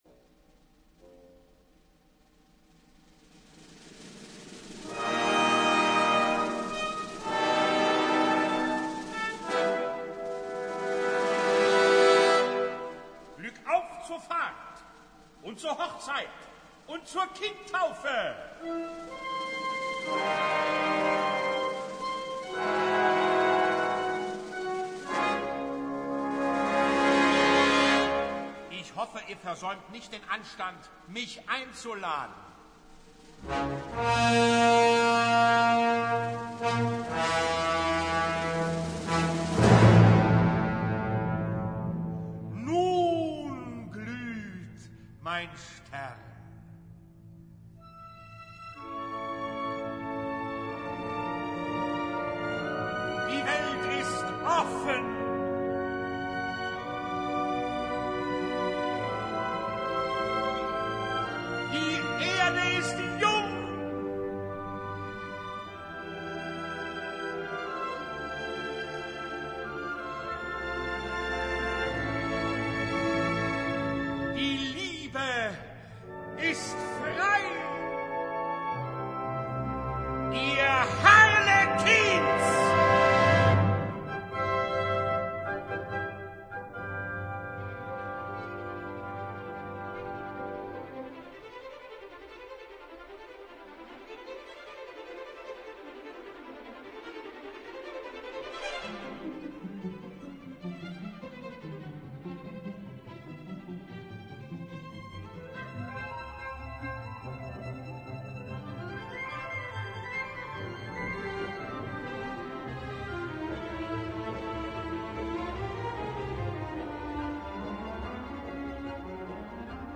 Capricho teatral en un acto, con libreto y música de Ferruccio Busoni.